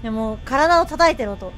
Worms speechbanks
Fireball.wav